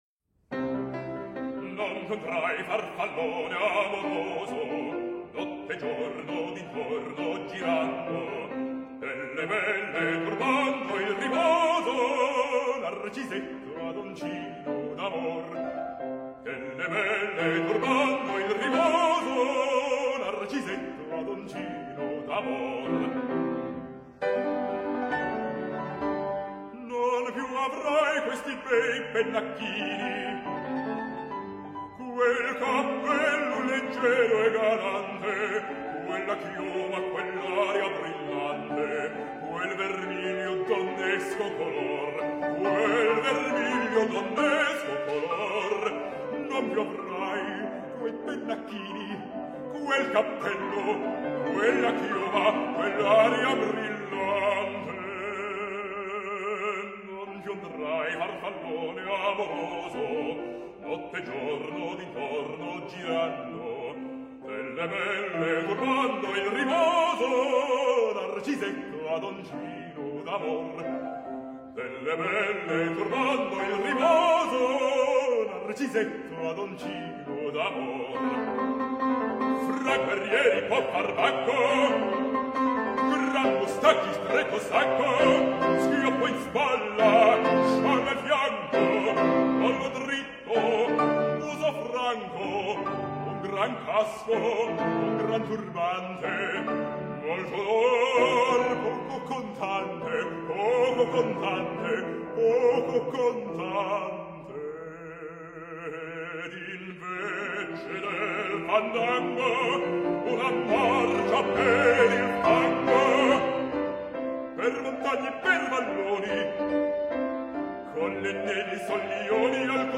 BARÍTONO